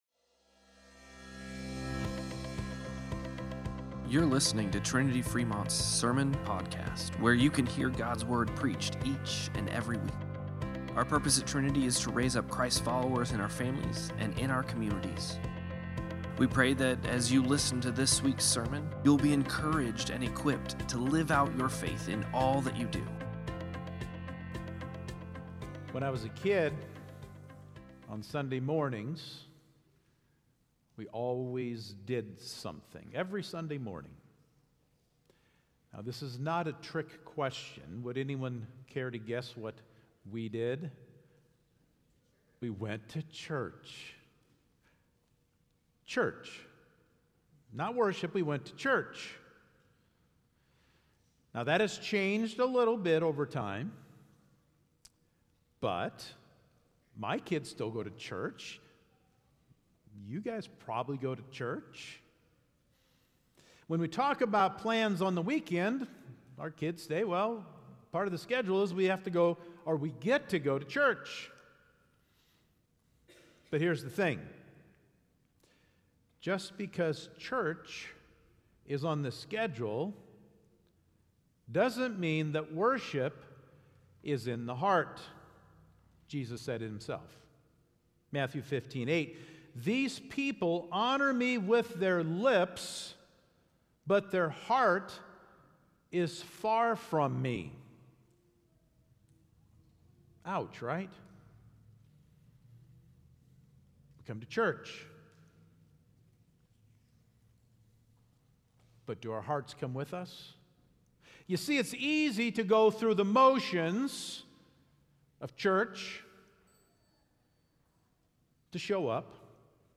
07-27-Sermon-Podcast.mp3